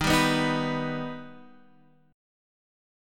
D#+ chord